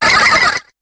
Cri de Spinda dans Pokémon HOME.